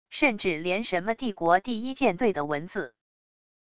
甚至连什么帝国第一舰队的文字wav音频生成系统WAV Audio Player